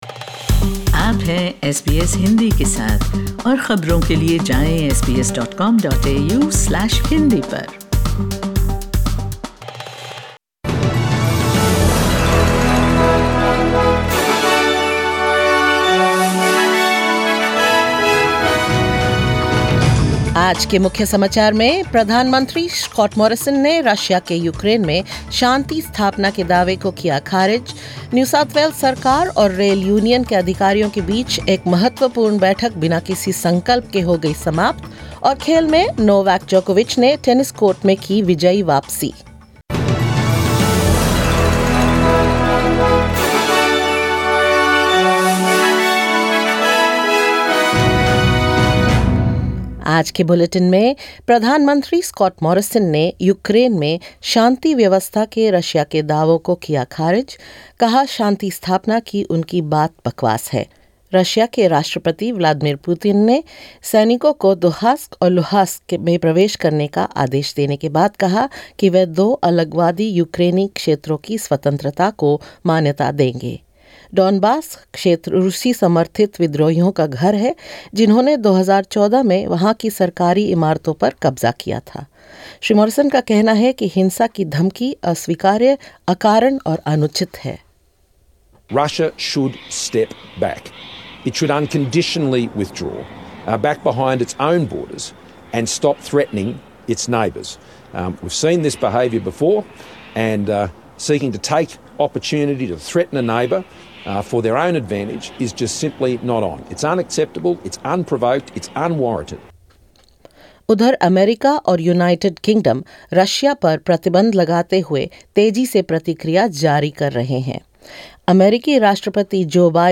In this latest SBS Hindi bulletin: Prime Minister Scott Morrison dismisses Russia's claims of peacekeeping in Ukraine as nonsense; New South Wales government in talks with Transport workers' union to resolve an industrial dispute causing train limitations; Victoria to ease mask rules in most indoor settings and more.